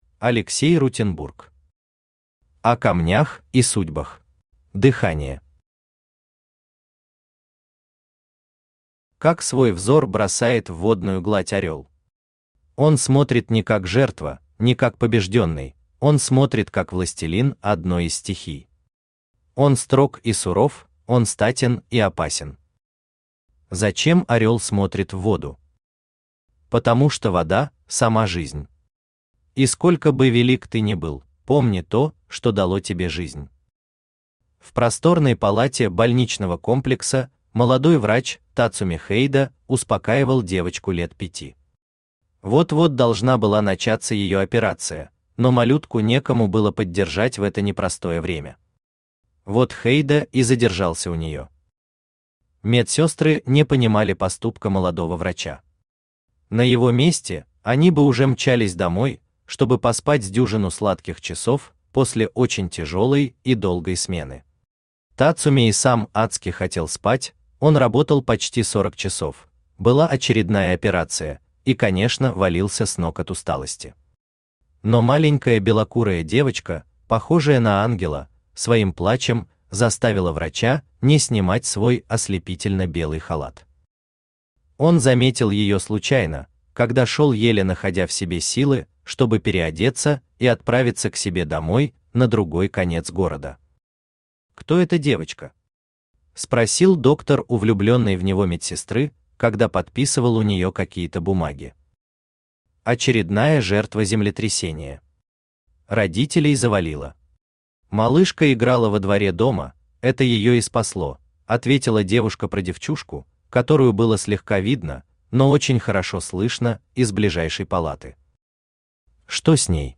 Аудиокнига О камнях и судьбах | Библиотека аудиокниг
Aудиокнига О камнях и судьбах Автор Алексей Михайлович Рутенбург Читает аудиокнигу Авточтец ЛитРес.